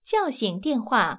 ivr-wakeup_call.wav